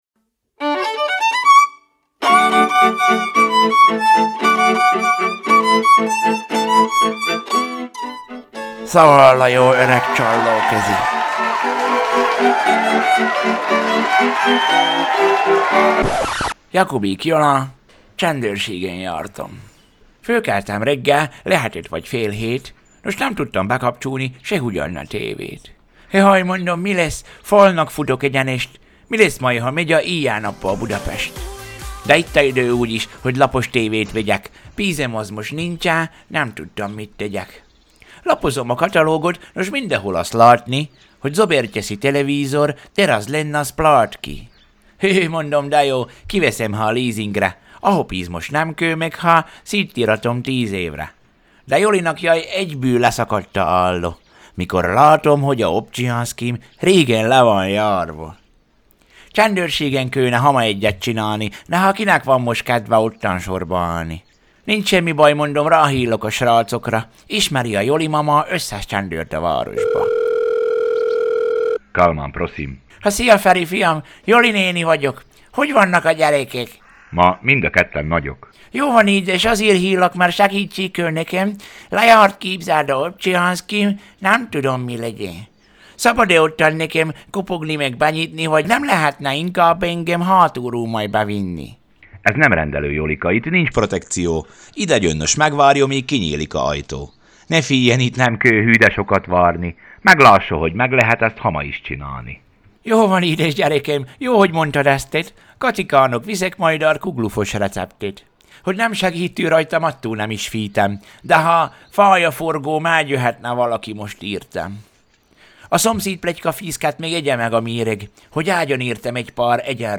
Zene: Pósfa zenekar – Ó, én édes komámasszony, 2Pac - Open Fire